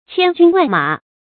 注音：ㄑㄧㄢ ㄐㄩㄣ ㄨㄢˋ ㄇㄚˇ
千軍萬馬的讀法